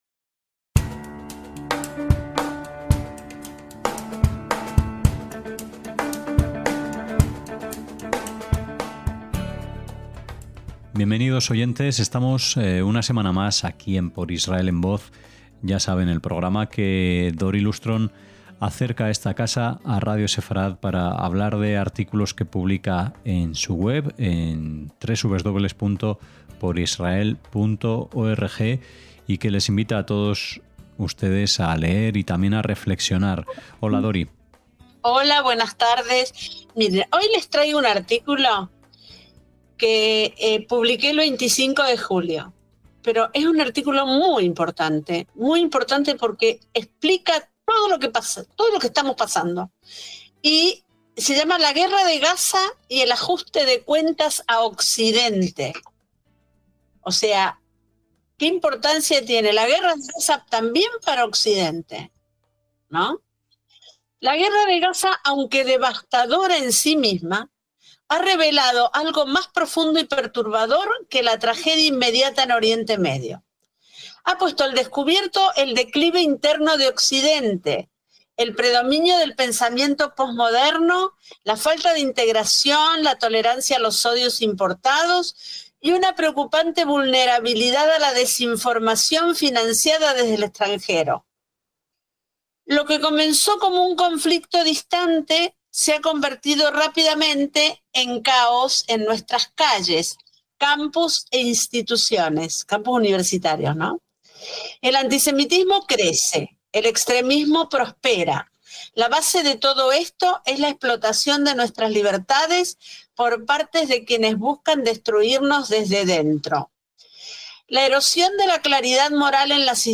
Escuchamos este artículo de Andrew Fox